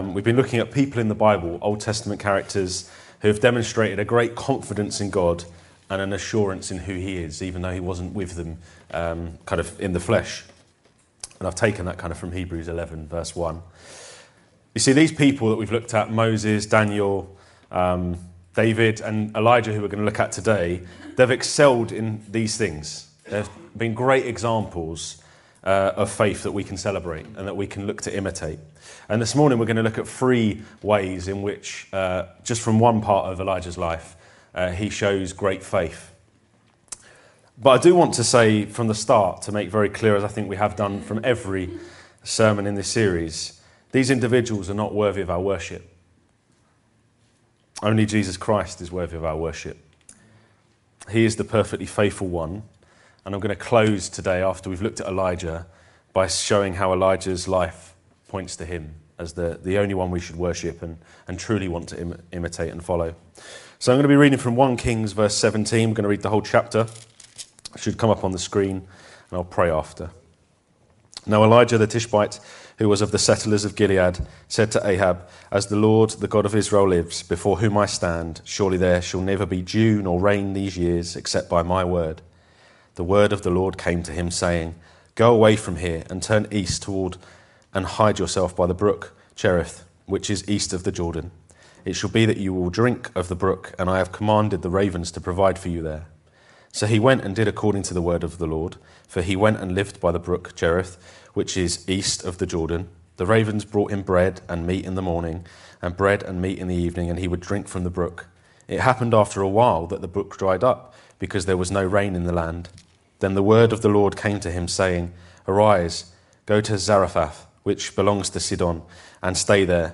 This sermon looks at how Elijah displayed an amazing example of faith across his life.